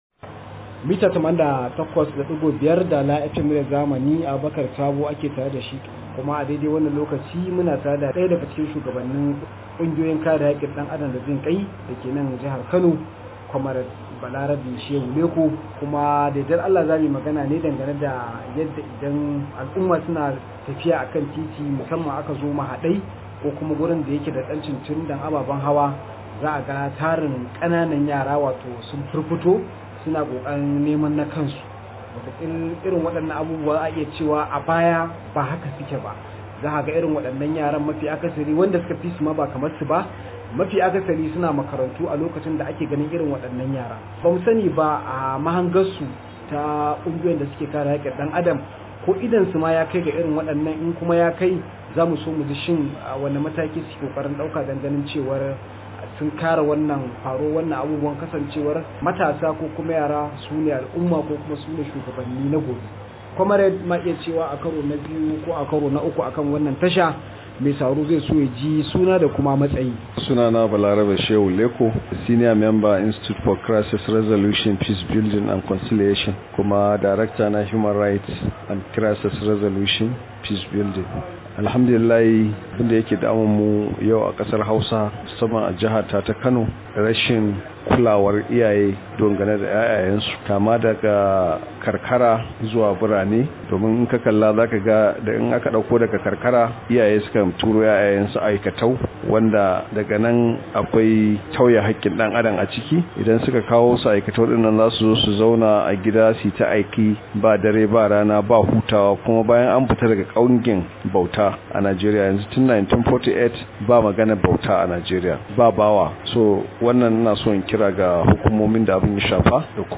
Rahoto: Za mu gurfanar da masu kai kananan yara aikatau – Human Right